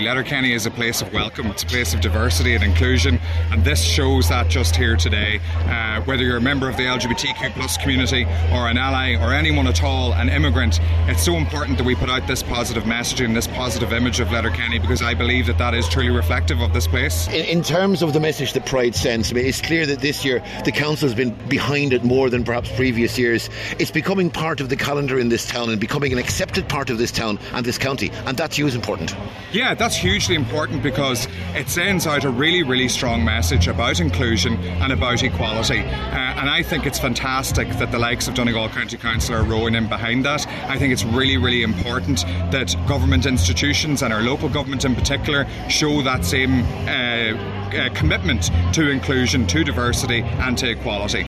Speaking as the parade was getting underway, Cllr Declan Meehan said the central message of Pride is everyone is welcome and everyone is loved…….